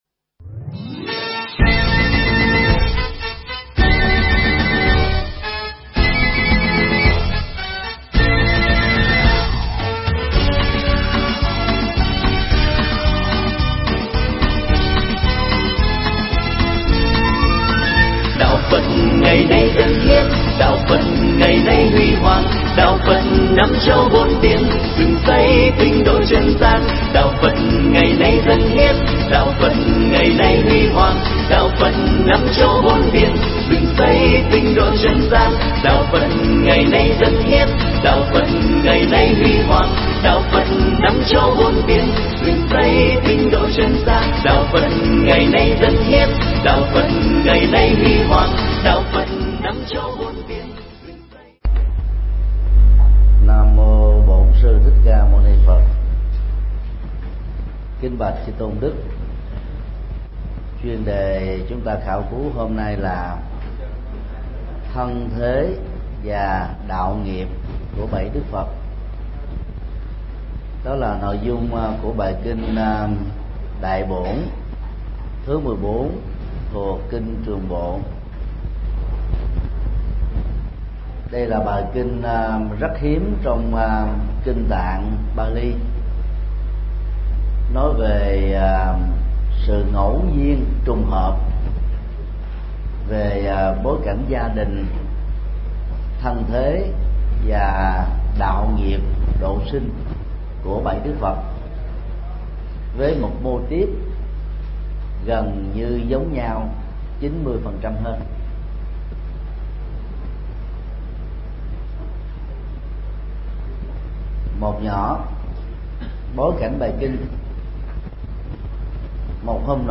Mp3 Kinh Trường bộ 14 – Kinh Đại bổn – Thân thế và đạo nghiệp của bảy đức Phật – Thầy Thích Nhật Từ Giảng tại chùa Tường Vân, Huyện Bình Chánh, TP.HCM, ngày 13 tháng 6 năm 2014